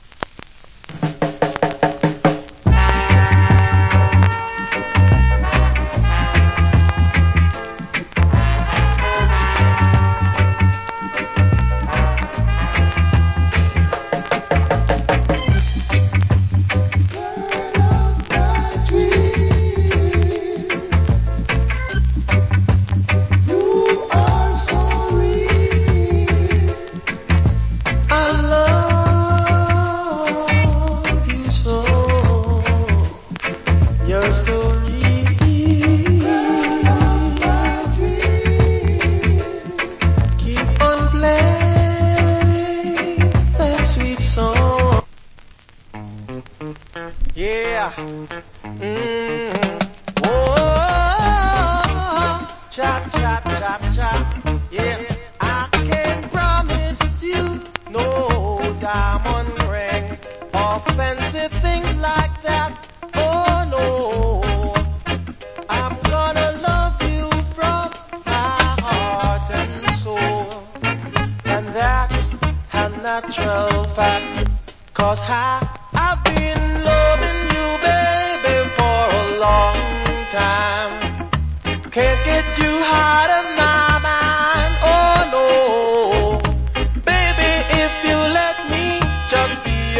Cool Reggae Self Cover.